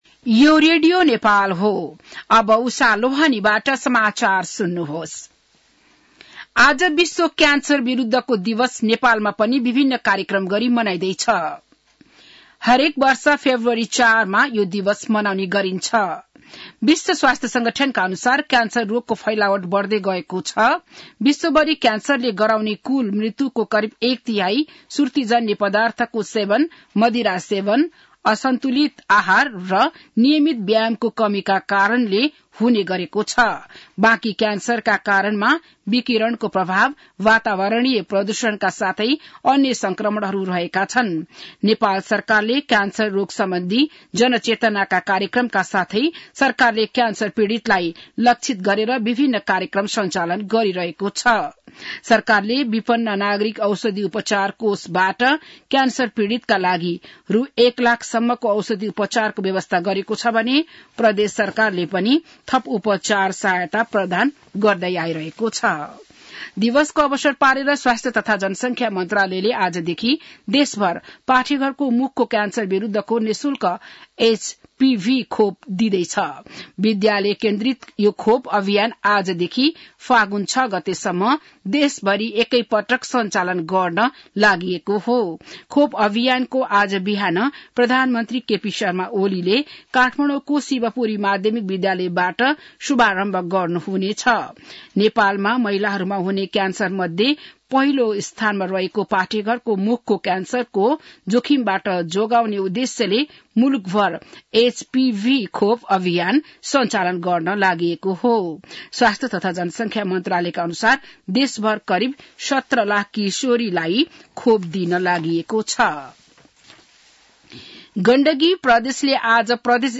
बिहान १० बजेको नेपाली समाचार : २३ माघ , २०८१